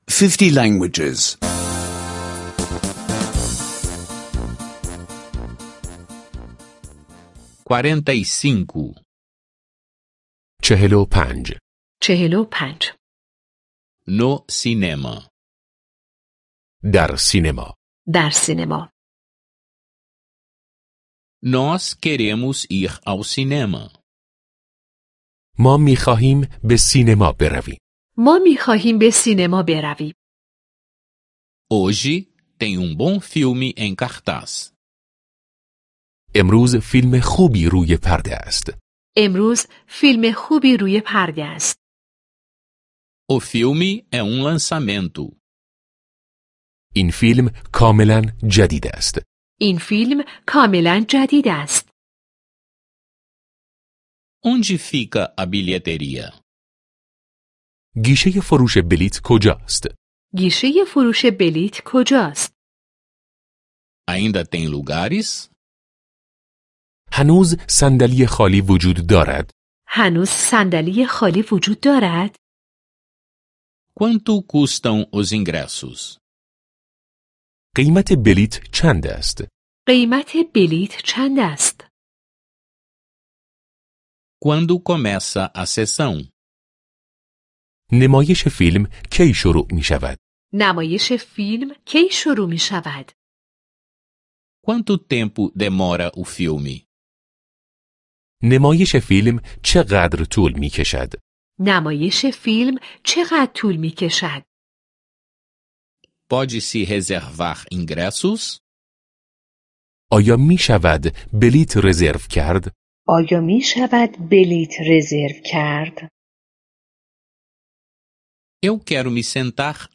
Aulas de persa em áudio — download grátis